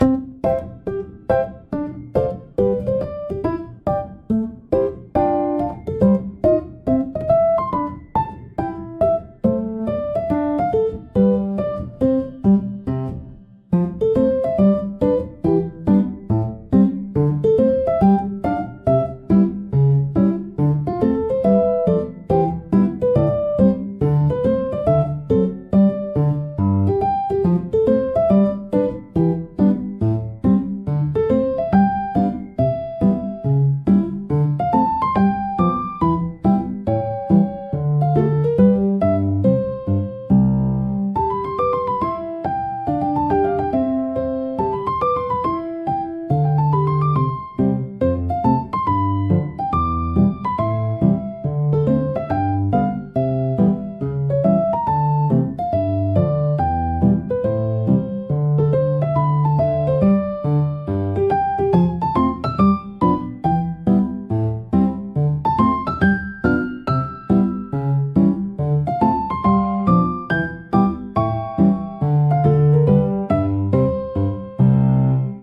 ゆったりとした軽快なピアノソロが日常のさりげないシーンを優しく彩るジャンルです。
シンプルなメロディラインが心地よいリズムを生み、穏やかながらも前向きなムードを演出します。
ピアノの柔らかなタッチが集中をサポートし、疲れを癒す効果を発揮します。